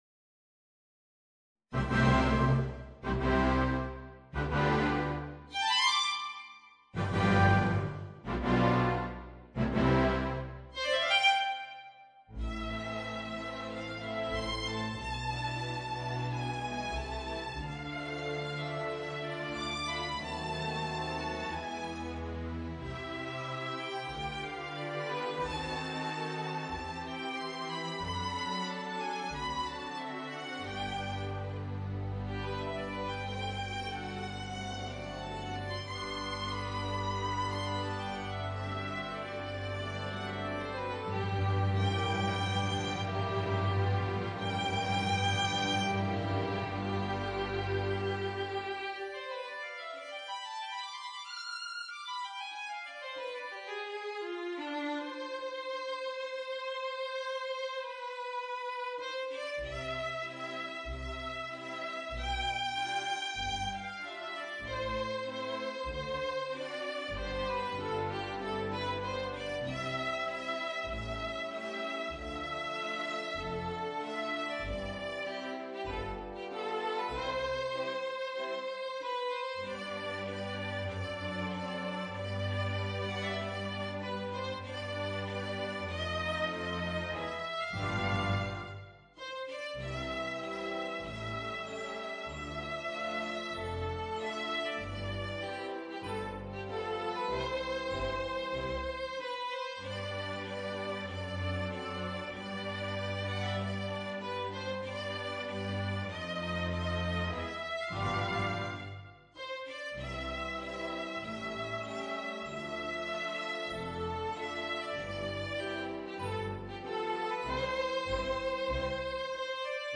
Voicing: Viola and Orchestra